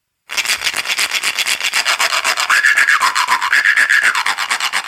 アホコ 細棒タイプ アフリカ民族楽器 （p040-51）
細棒タイプは繊細なタッチで奏でる独特のリズムが魅力。
ギザギザの棒を滑らせるだけで、乾いたガラガラ音が響きます。
さらにジュジュの実を使うことで、音が深みを増し、表情豊かに変化。
シンプルながら奥深い音の変化を楽しめる、唯一無二のパーカッションです。
この楽器のサンプル音